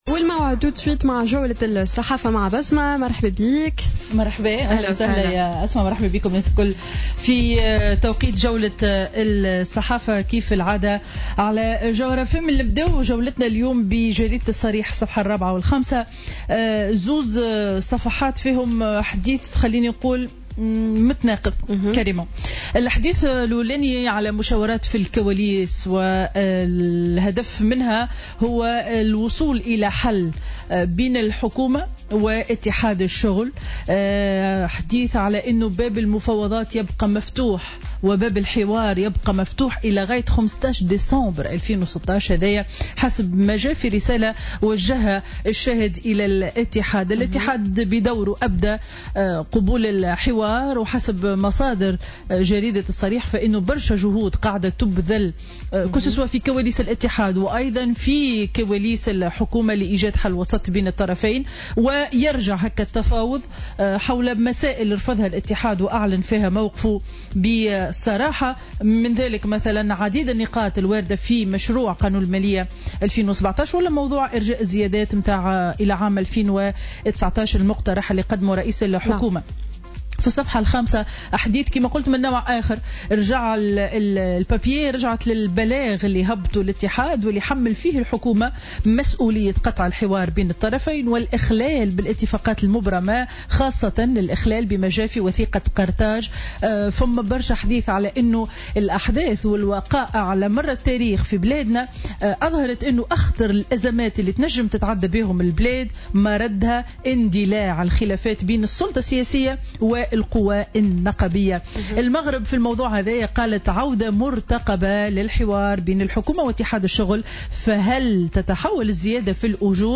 Revue de presse du mercredi 19 octobre 2016